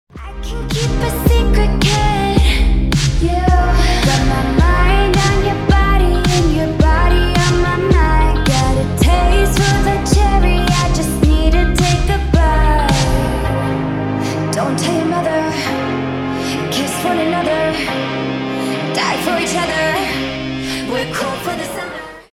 чувственные
красивый женский голос